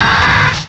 pokeemerald / sound / direct_sound_samples / cries / grotle.aif